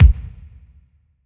• Long Room Reverb Kick Single Hit D Key 282.wav
Royality free kick single hit tuned to the D note. Loudest frequency: 203Hz
long-room-reverb-kick-single-hit-d-key-282-YCk.wav